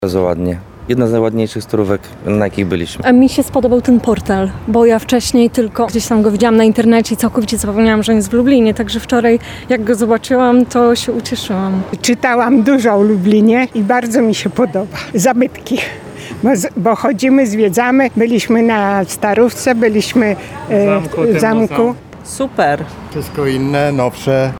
Jest super – mówią turyści spotkani przez reportera Radia Lublin.